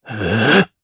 Zafir-Vox_Casting2_jp.wav